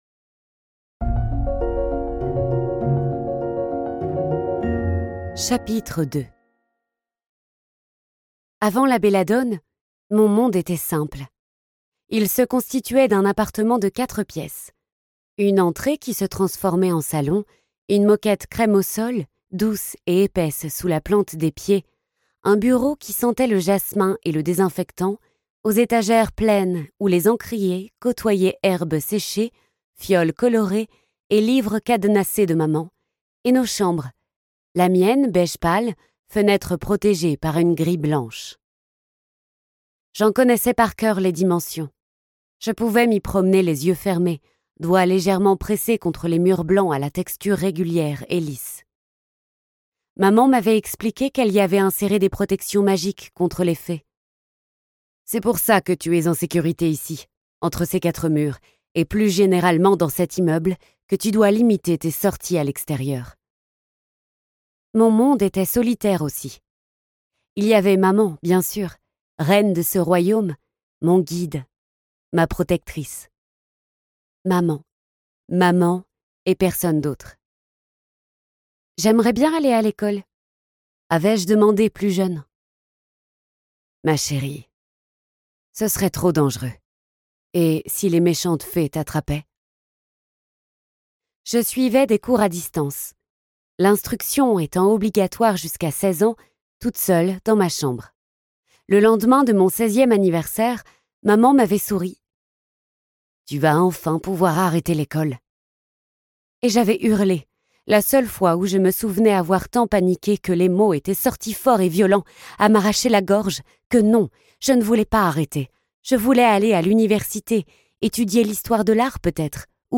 Mais à chaque carte tirée, le jeu s'immisce un peu plus dans son esprit, et Alicia ne réalise pas qu'elle devient le jouet de forces sombres et maléfiques...Ce livre audio est interprété par une voix humaine, dans le respect des engagements d'Hardigan.